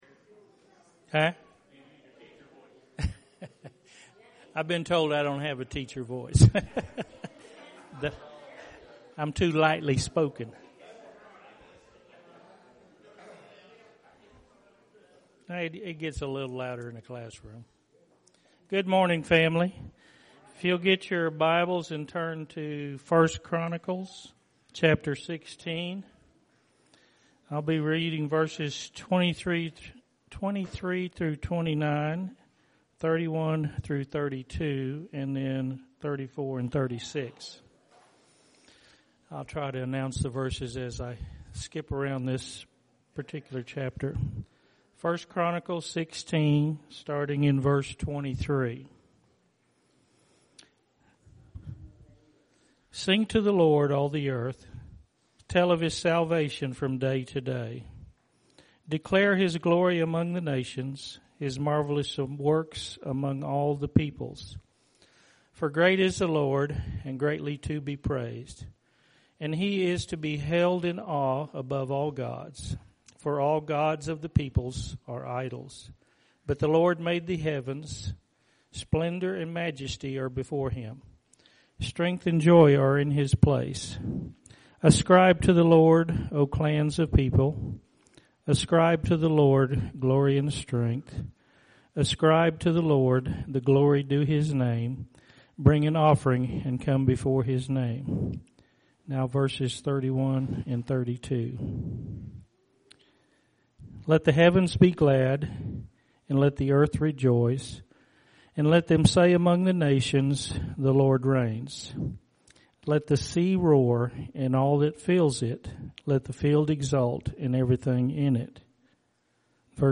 October 18th – Sermons